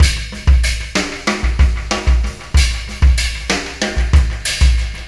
Pulsar Beat 21.wav